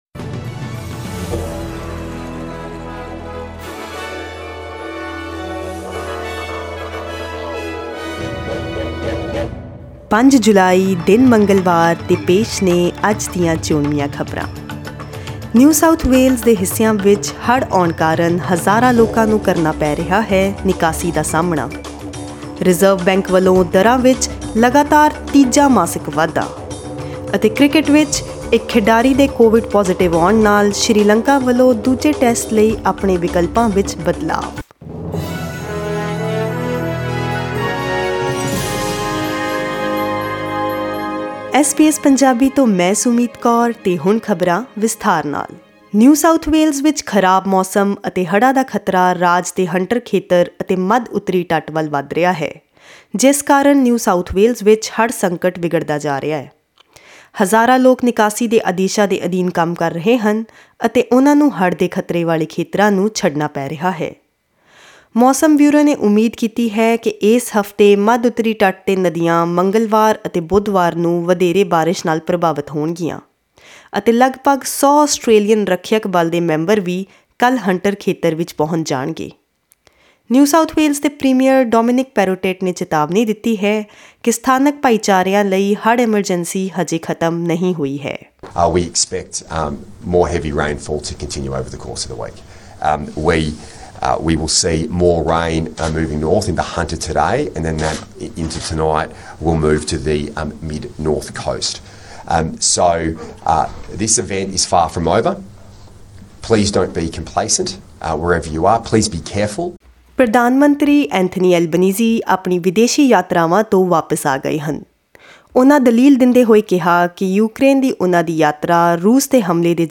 Click on the audio button to listen to the news bulletin in Punjabi.